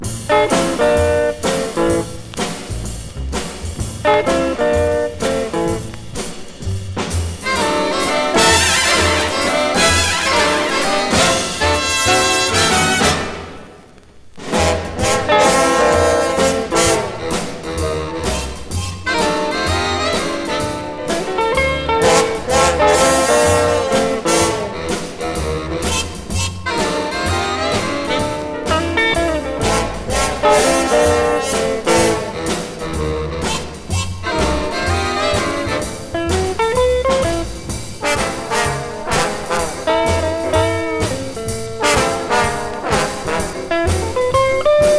躍動感溢れる演奏、艶やかでふくよかなトーンと聴きどころ満載の良盤です。
Jazz　USA　12inchレコード　33rpm　Mono